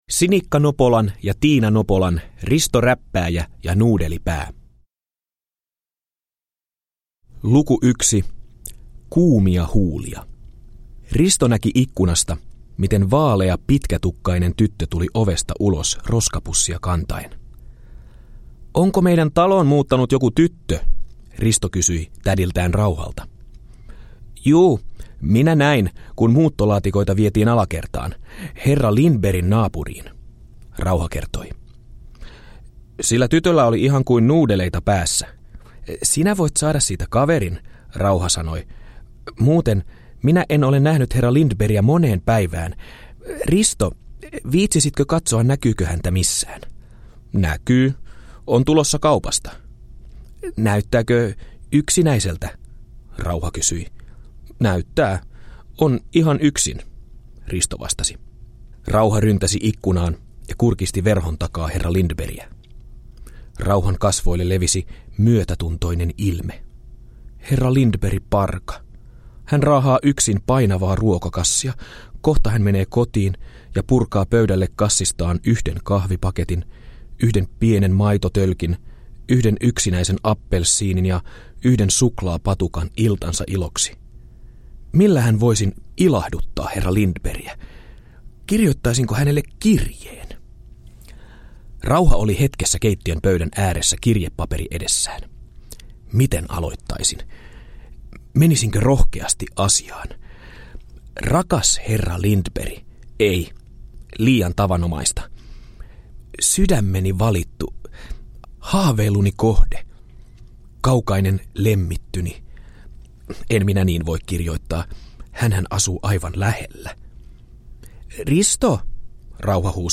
Risto Räppääjä ja Nuudelipää – Ljudbok